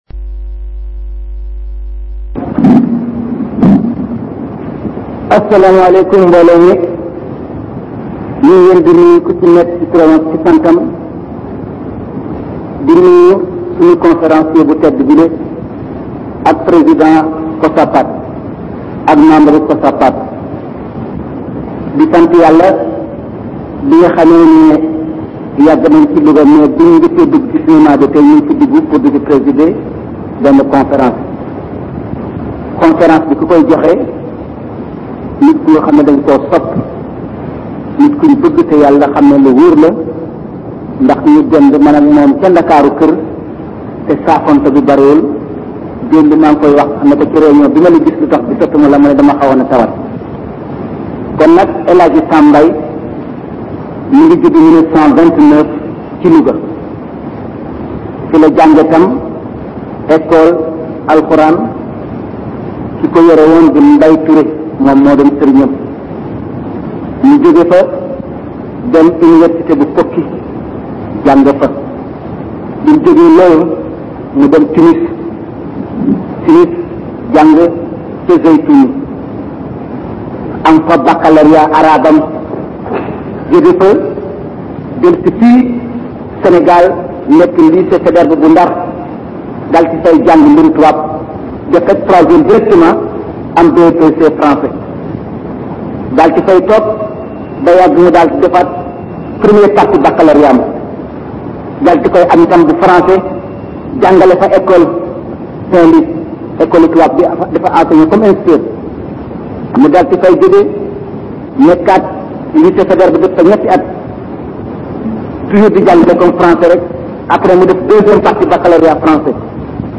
Conférence organisée par le COSAPAD : Comité de soutien à l’action du Président Abdou Diouf